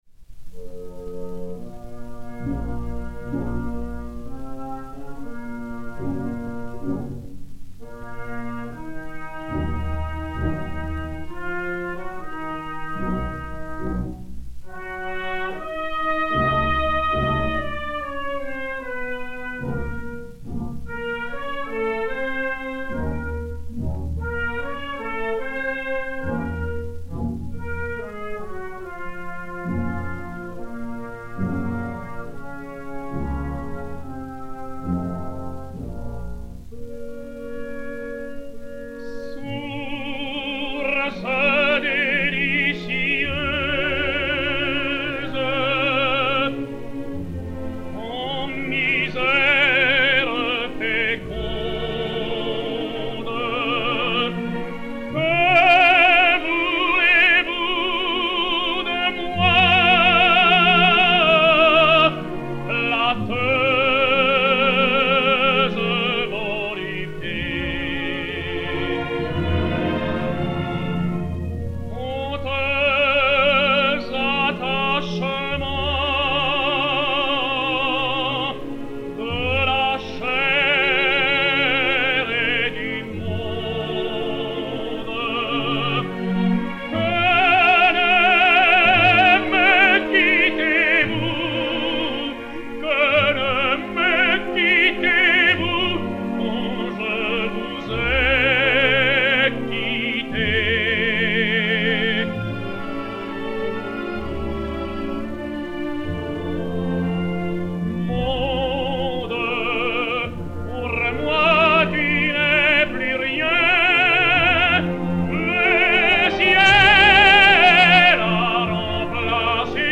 José Luccioni (Polyeucte) et Orchestre Symphonique dir Eugène Bigot
Gramophone DB 11.115, mat. 2LA421-1, enr. à Paris le 03 juin 1946